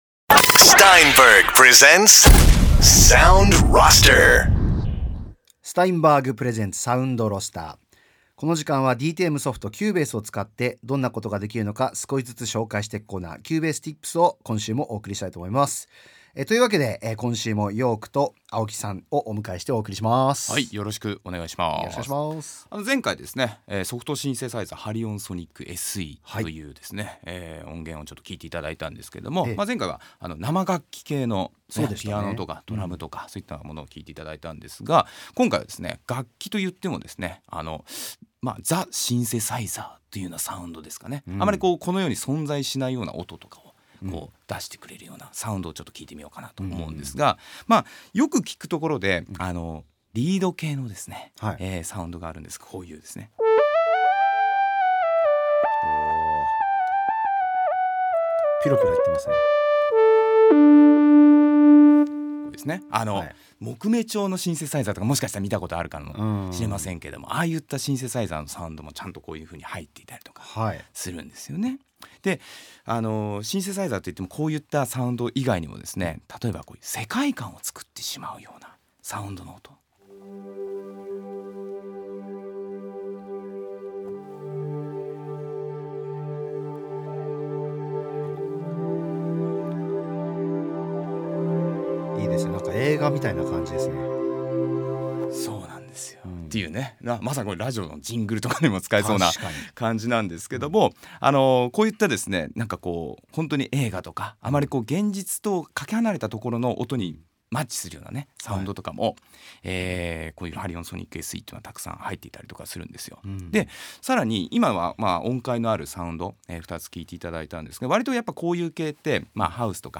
Steinberg が提供するラジオ番組「Sound Roster」。
今回は様々な「電子楽器らしい」サウンドを紹介していきます。